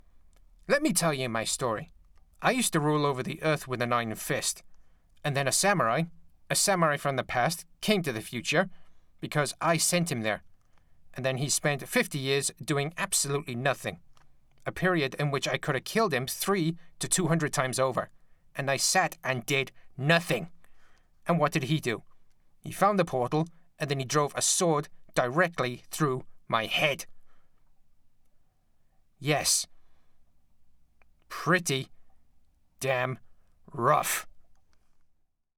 Voice Over Work